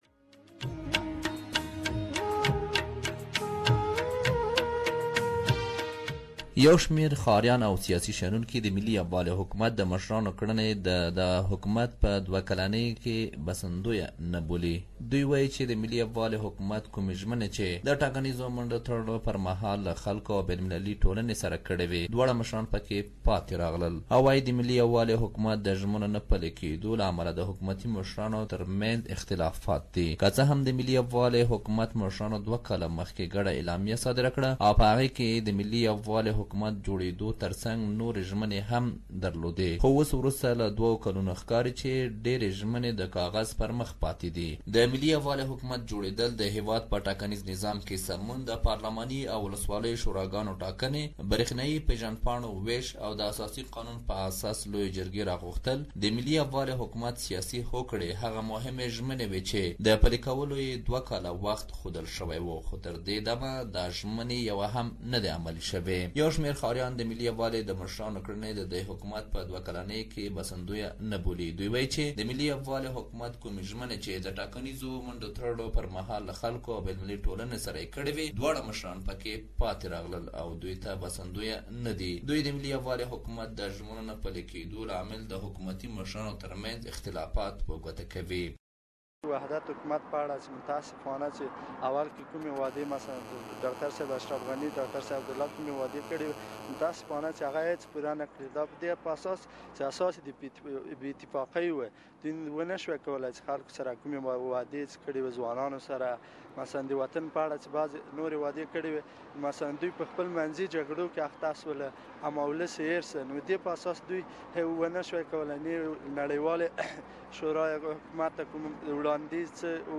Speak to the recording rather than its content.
We have asked ordinary Afghans on the street their opinion on the unity government.